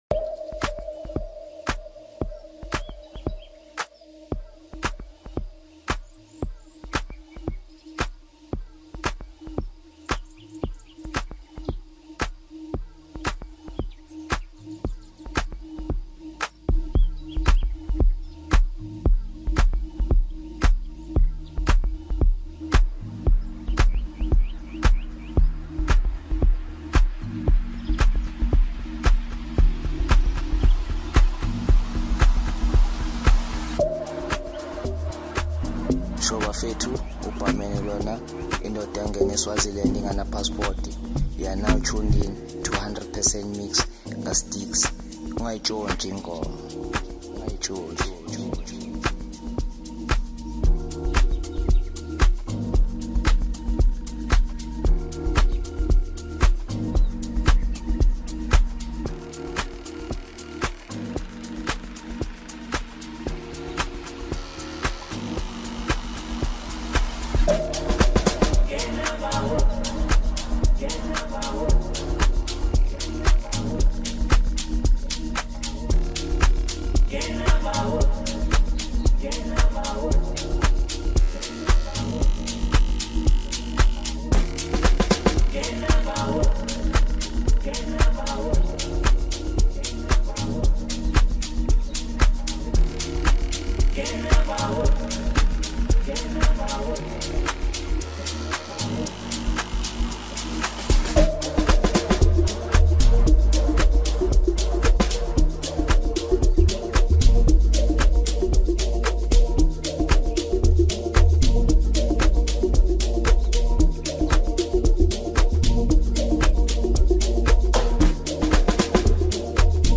Song Genre: Amapiano Song.